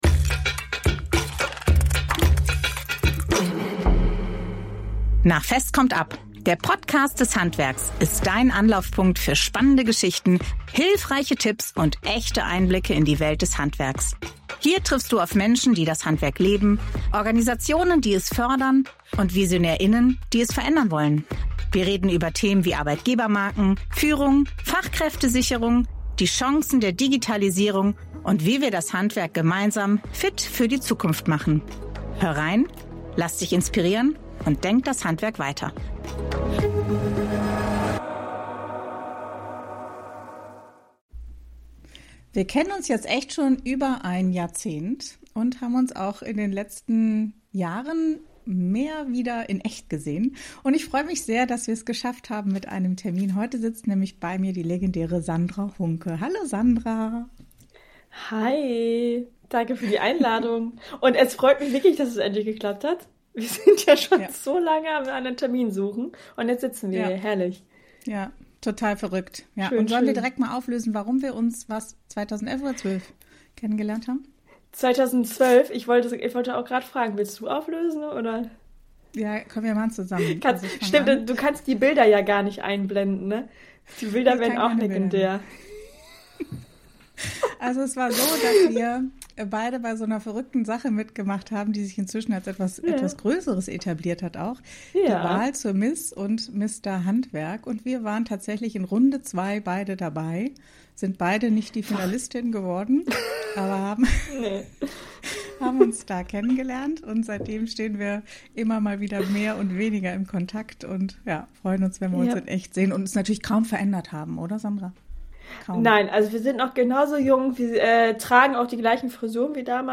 Wir sprechen über Gleichberechtigung im Handwerk, die Bedeutung von Selbstbewusstsein und die Kraft unterstützender Beziehungen. Ein Gespräch über Wachstum, Selbstvertrauen und die Frage, was uns wirklich trägt.